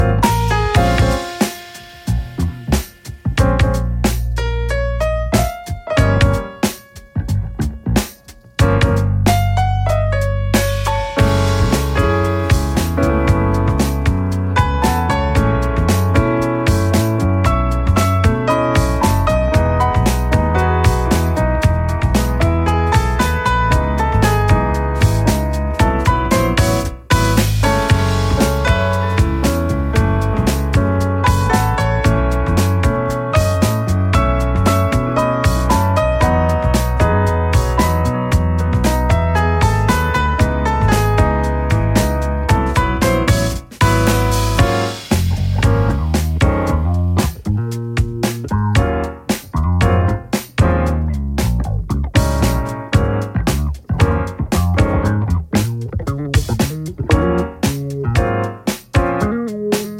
ジャンル(スタイル) JAZZ / POP JAZZ